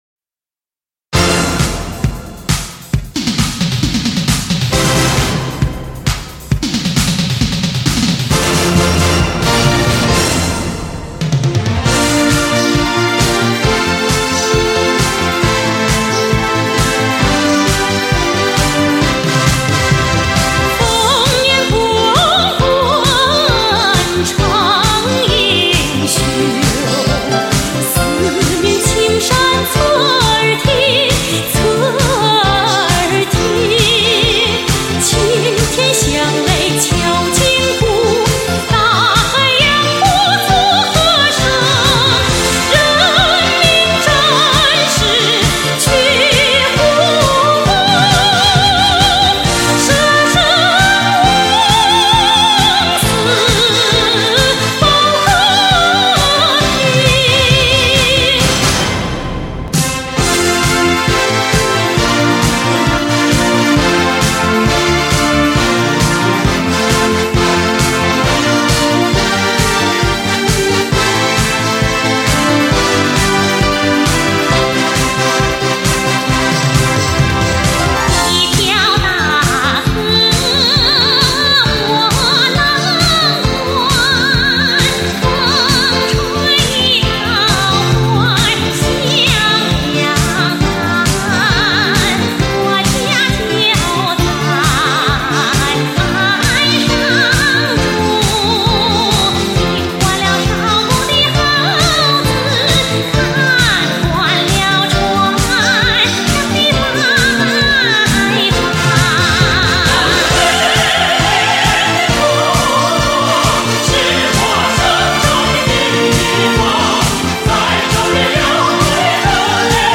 经典民歌优秀电影插曲，接连不断的演唱，连续不断的精彩。